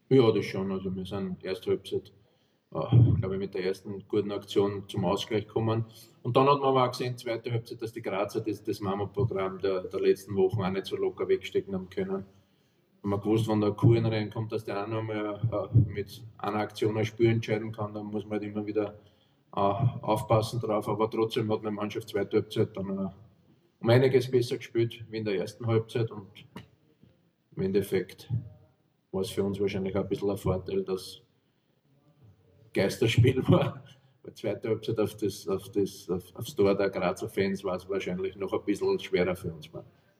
Herzog-Meister-der-Effizienz.wav